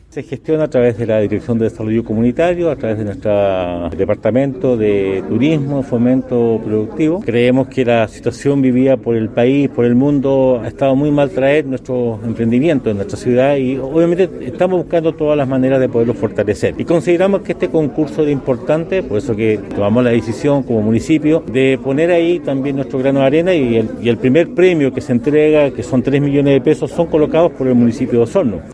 Por su parte, el alcalde de Osorno, Emeterio Carrillo, dio a conocer que el municipio aportó con fondos para el primer premio.